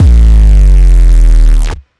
puredata/resources/808_drum_kit/classic 808/Pedal Bass Hit.wav at cf8e1b7e857aa28113f06ca140d8372ee6167ed9
Pedal Bass Hit.wav